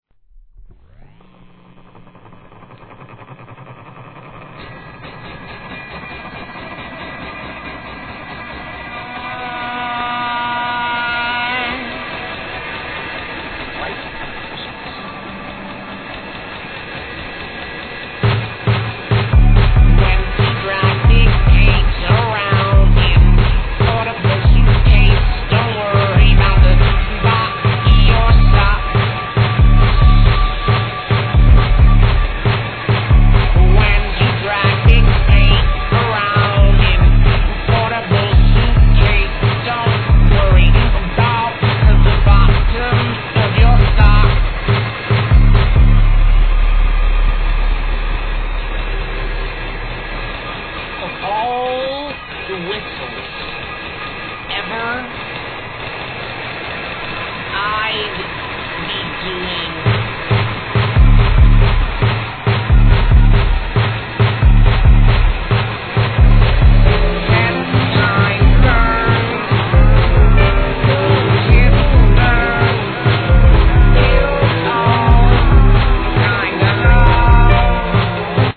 1. HIP HOP/R&B
フォーク、ブルース、ロックからヒップホップ・ブレイクと融合させるもはや垣根は逆さの世界。